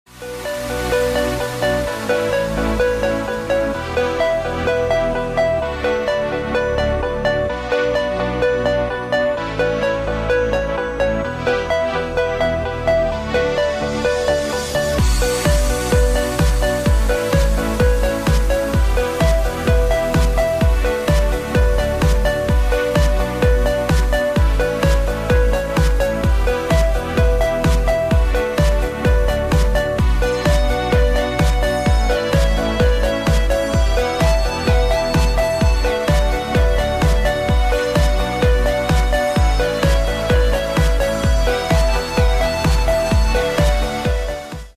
• Качество: 128, Stereo
красивые
dance
Electronic
электронная музыка
спокойные
без слов
club
house
Жанр: Танцевальная/электронная музыка.